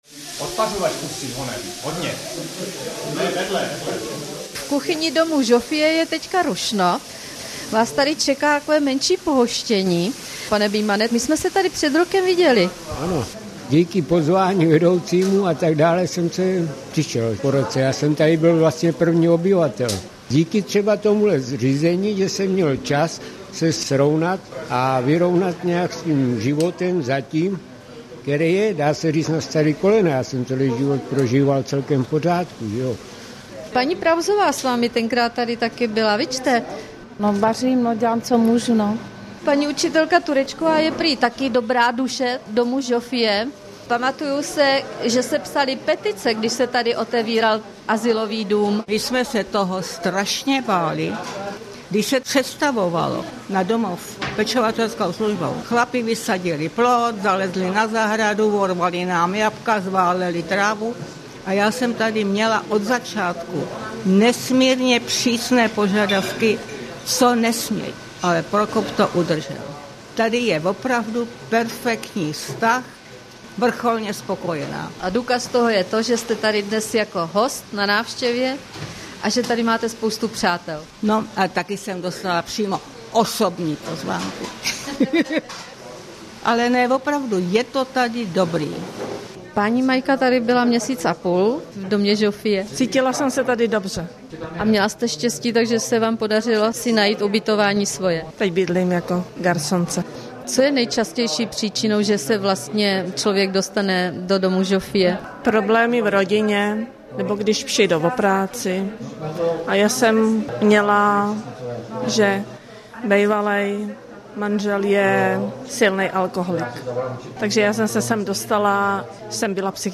rozhlasová nahrávka Českého rozhlasu Hradec Králové